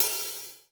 HI-HAT - 11.wav